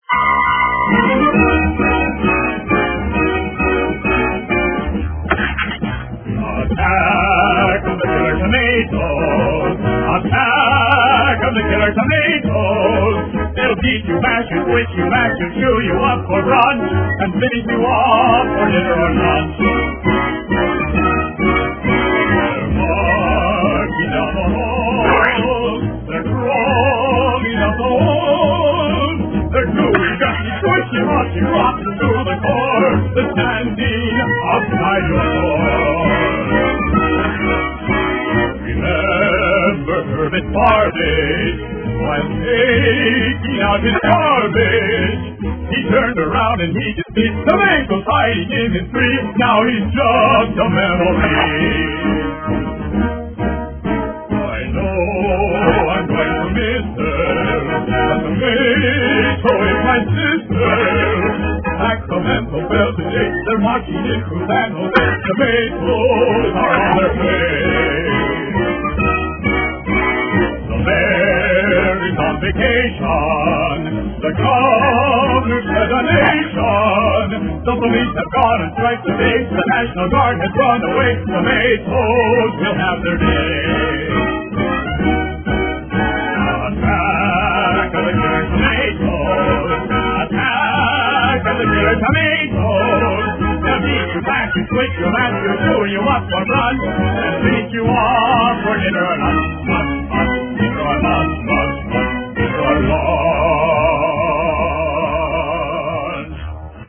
それでは最後に、脳の記憶中枢に深く抉りこんで絶対に消えない、低音の魅力溢れる名曲を貴方に捧げて筆を置きたい。